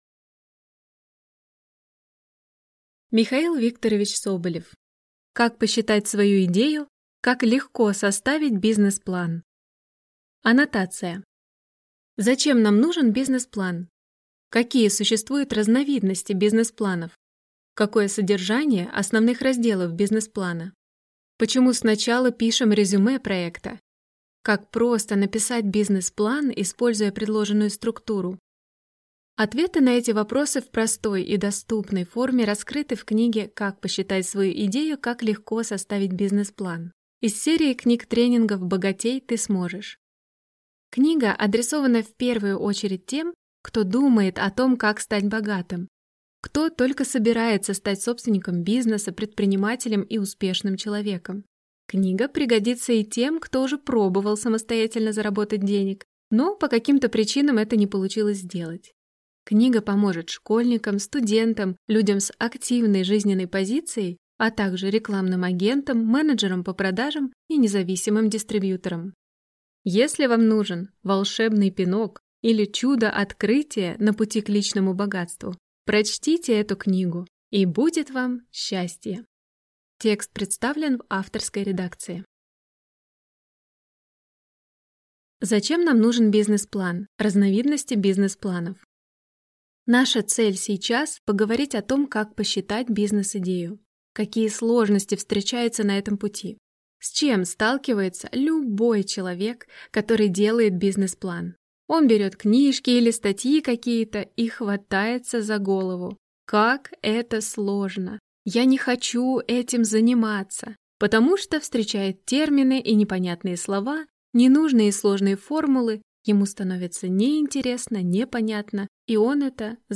Аудиокнига Как посчитать свою идею? Как легко составить бизнес-план?